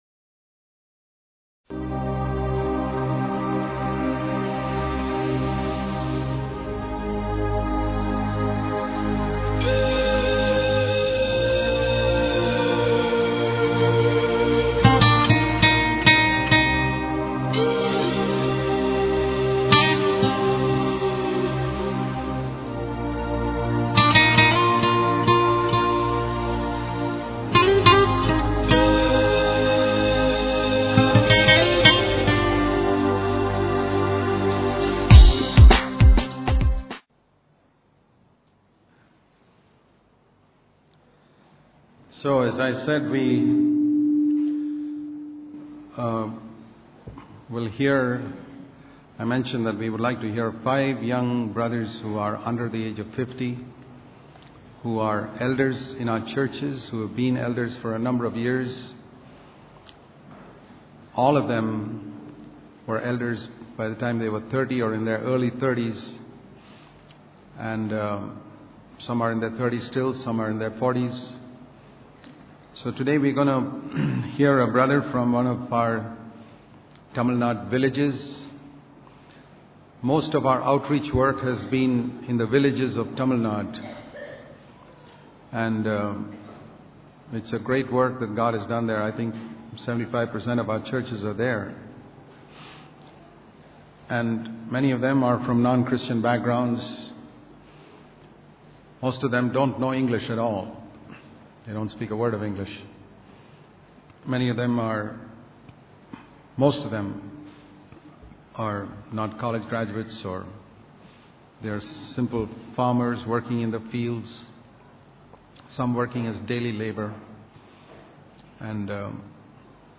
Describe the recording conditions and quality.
How To Possess The Land Fully New Wine In New Wineskins The live streamed messages spoken during the 2011 Bangalore Conference.